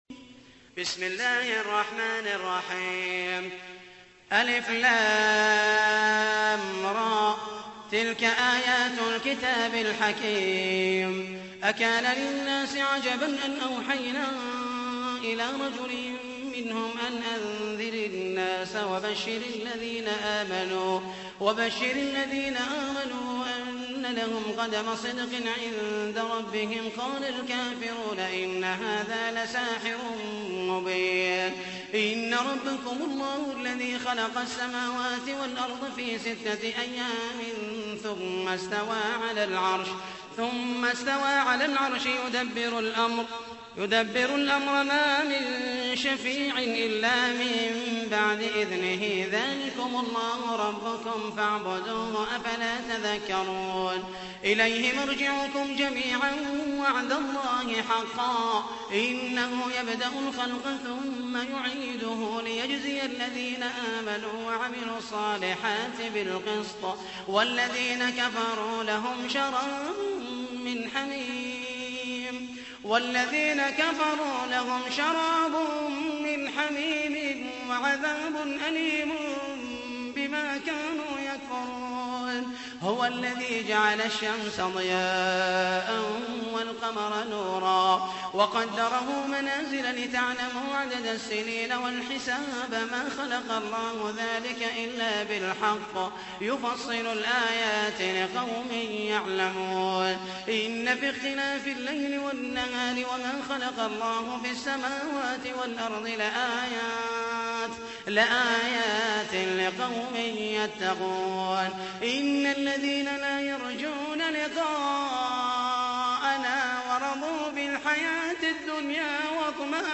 تحميل : 10. سورة يونس / القارئ محمد المحيسني / القرآن الكريم / موقع يا حسين